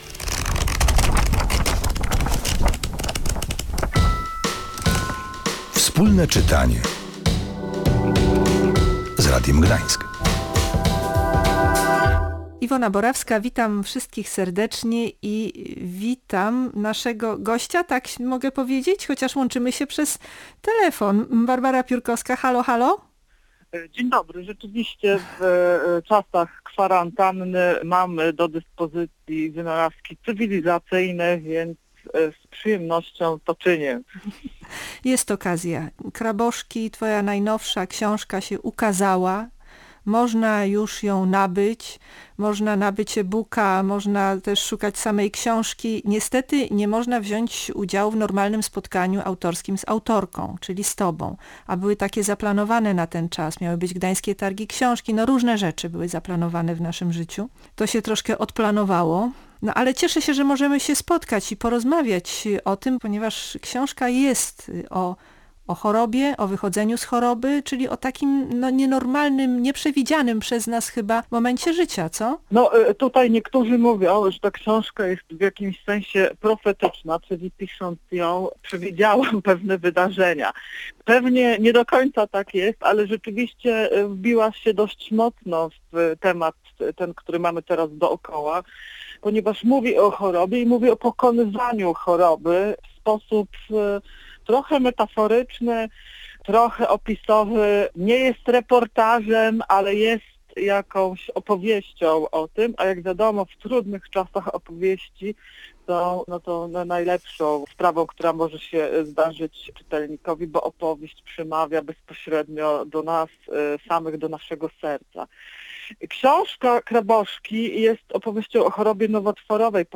Czy choroba może być naszym sprzymierzeńcem? O tym i o książce rozmawiamy w audycji Wspólne Czytanie z Radiem Gdańsk.